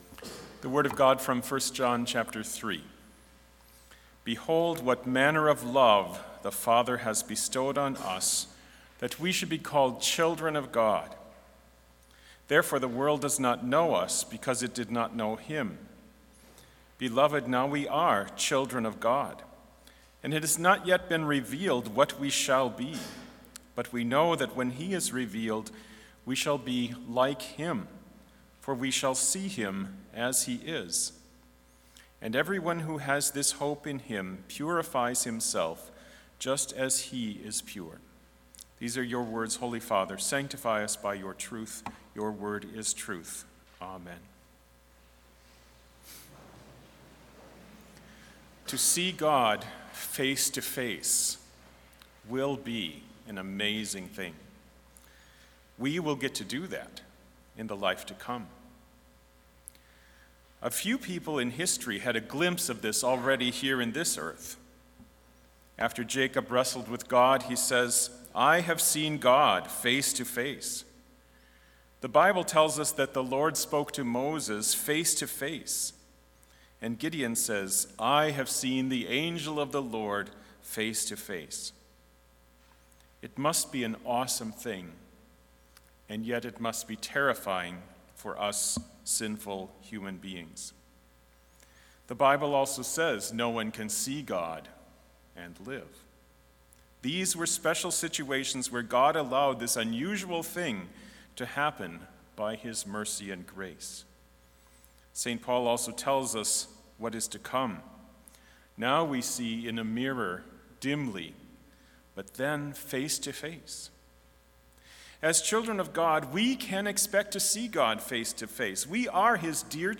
Complete Service
This Chapel Service was held in Trinity Chapel at Bethany Lutheran College on Tuesday, May 2, 2023, at 10 a.m. Page and hymn numbers are from the Evangelical Lutheran Hymnary.